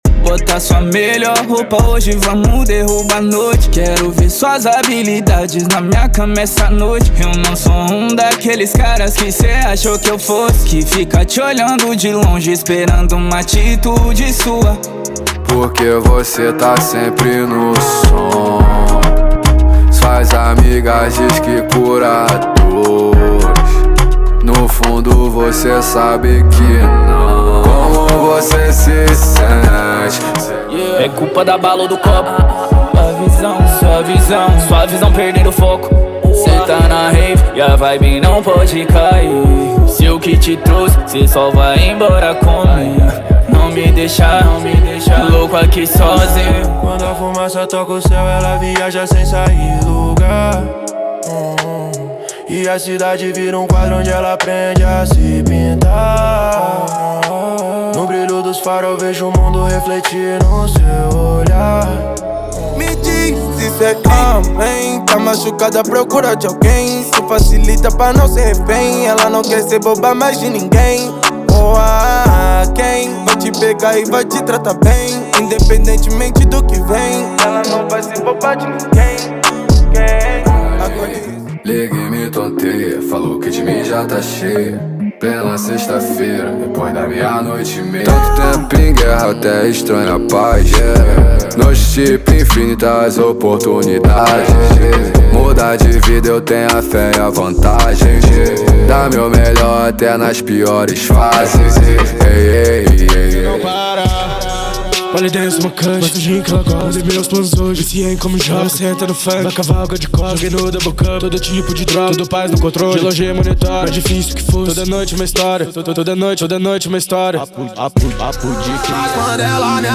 • Rap, Trap Nacional e Funk Ostentação = 50 Músicas
• Sem Vinhetas
• Em Alta Qualidade